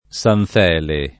San Felice [San fele] top.